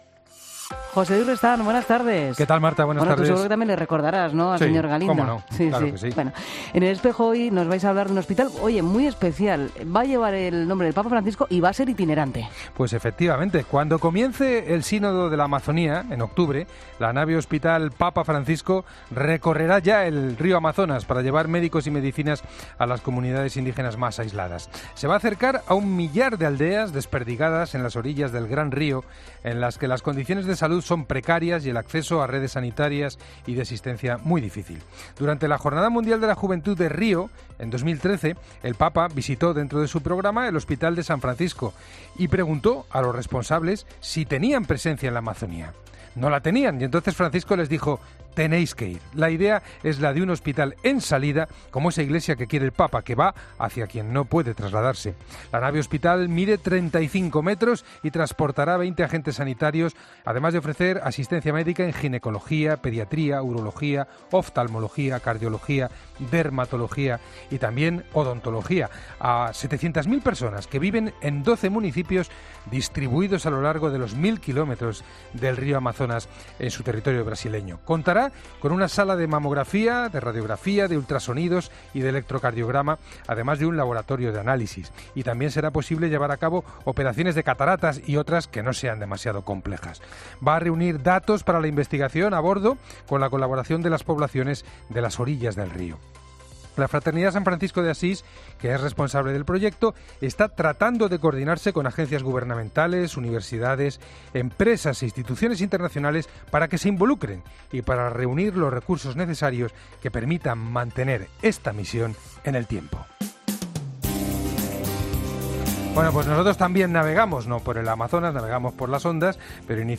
AUDIO: Hoy entrevistamos a Monseñor Jesús Sanz sobre los neuvos seminaristas que serán beatificados en Oviedo.